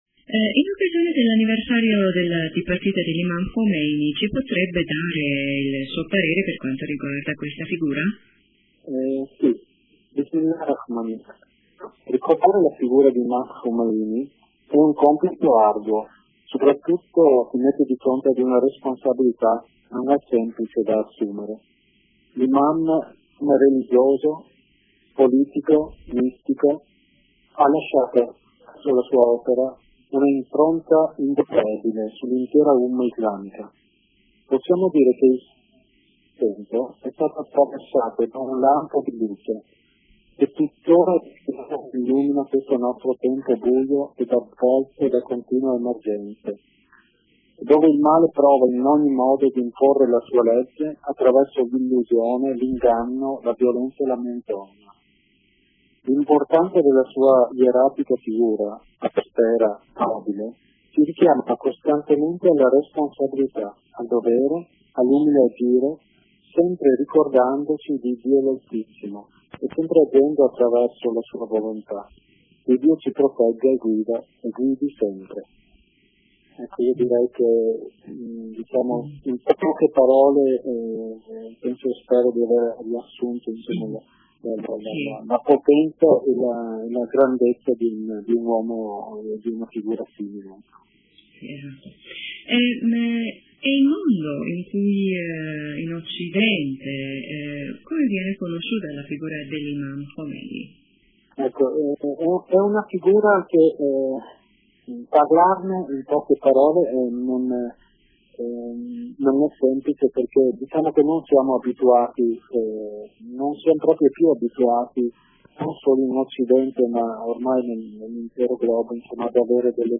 in un collegamento telefonico con Radio Italia